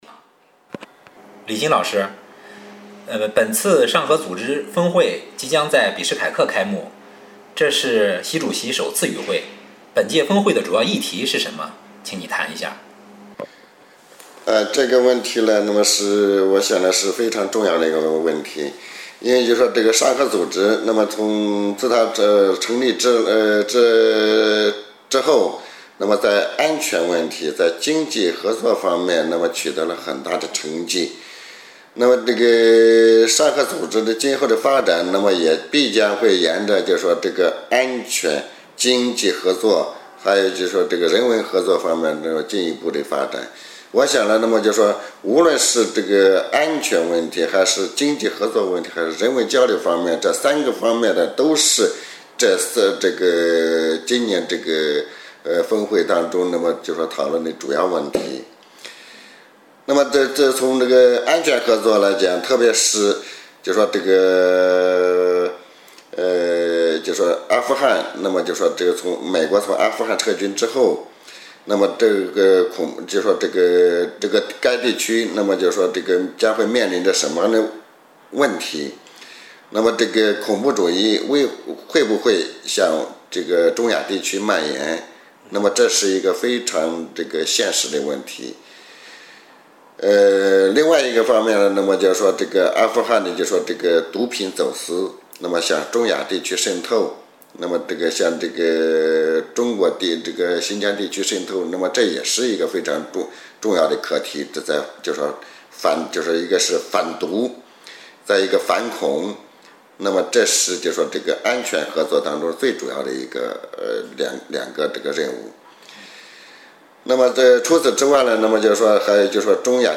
专家访谈：令人瞩目的上合组织峰会 2013-09-09 上合组织峰会将于9月13日在吉尔吉斯斯坦比什凯克举行，习主席将首次与会。本次峰会将通过批准和签署文件，进一步拓宽和加深该组织的合作领域。